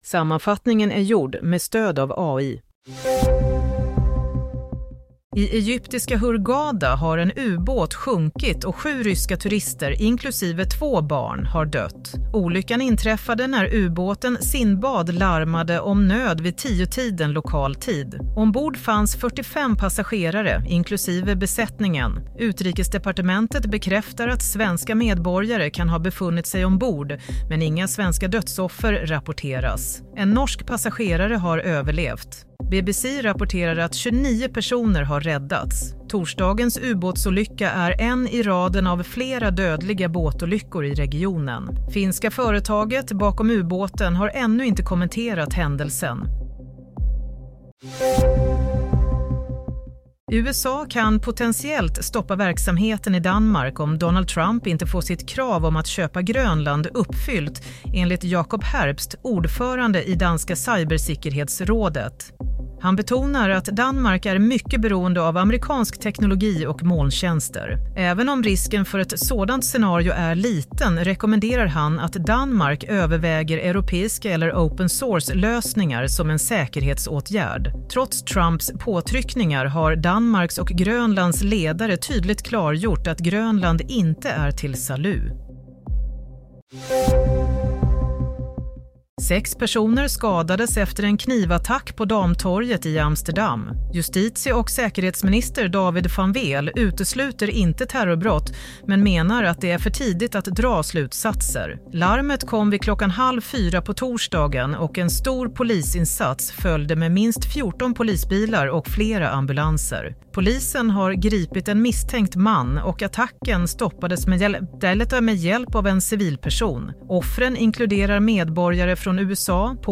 Nyhetssammanfattning – 27 mars 22:00